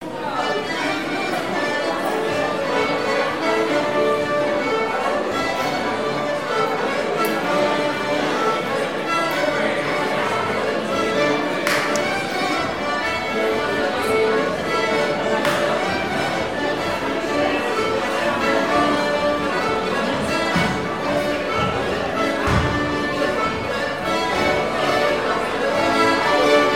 danse : branle : courante, maraîchine
Présentation lors de la sortie de la cassette audio
Pièce musicale inédite